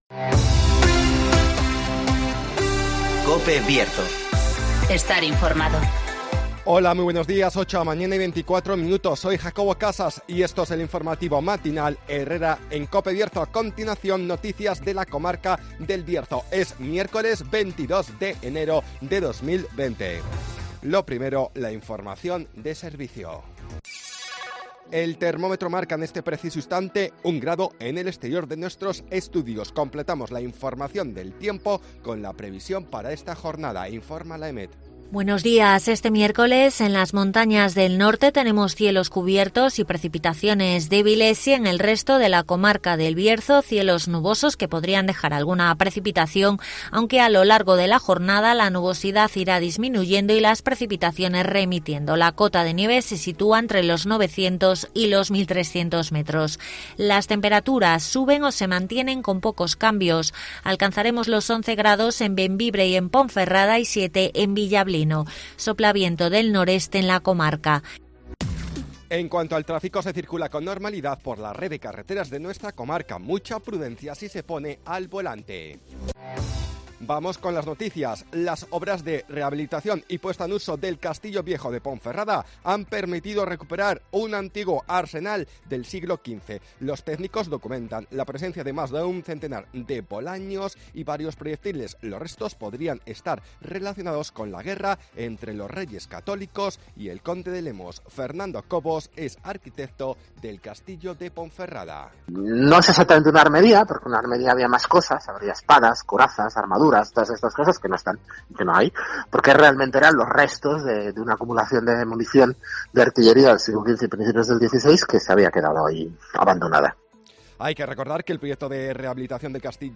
INFORMATIVOS BIERZO
Conocemos las noticias de las últimas horas de nuestra comarca, con las voces de los protagonistas